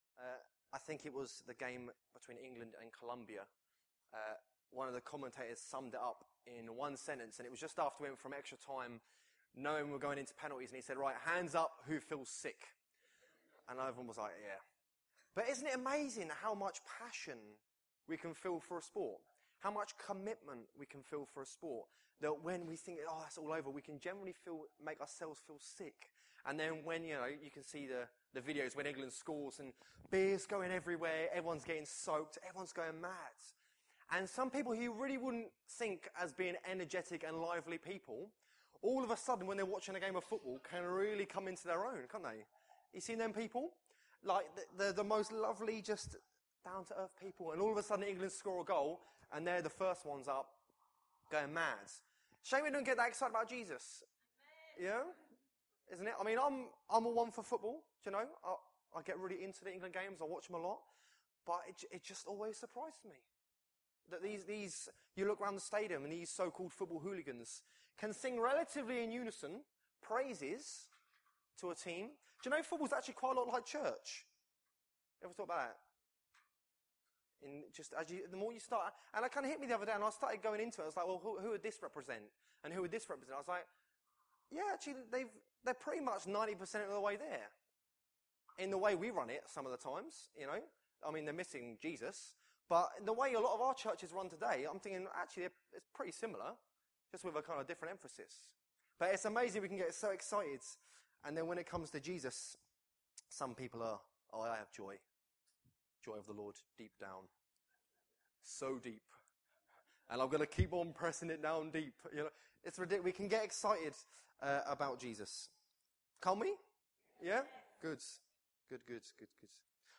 Due to technical problems there is a slight hum on the recording that prevented the end of the sermon to be heard so it therefore had to be cut short. sorry about this.
Service Type: Sunday Morning